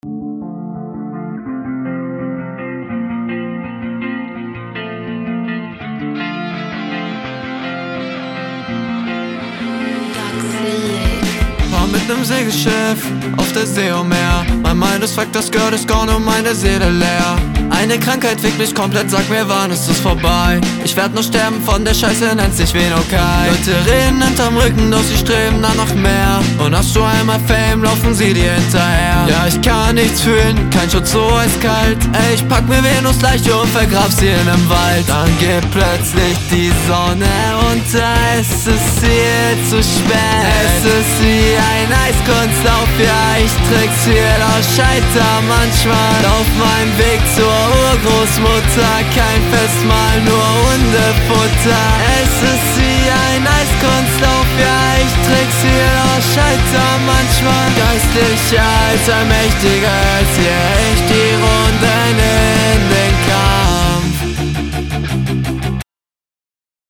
AUA MEINE OHREN DIESES AUTOTUNE IST WIE FINGERNÄGEL AN DER TAFEL FRÜHER Wo punchlines eigentlich?